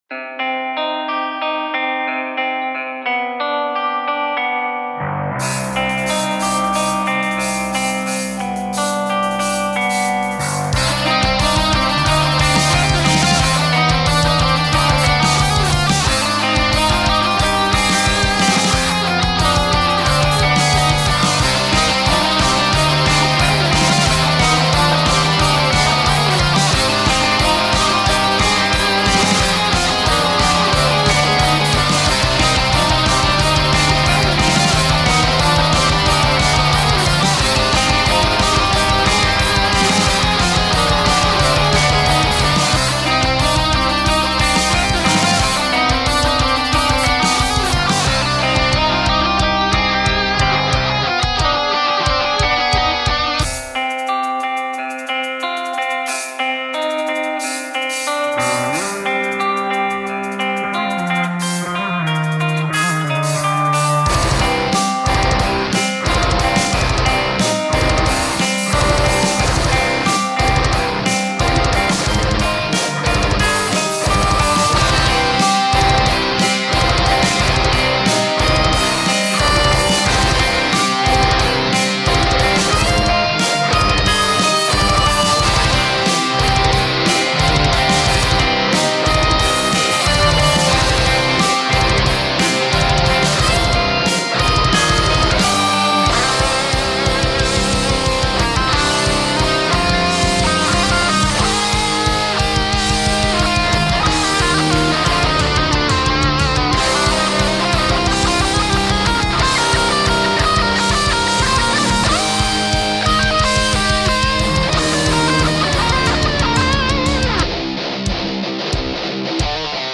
However I did get some rough recordings down back then...so I decided to revisit one and then share it with you (yay?).
Metal and guitar